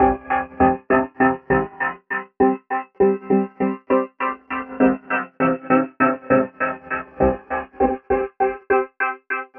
tx_synth_100_5thsweep_C.wav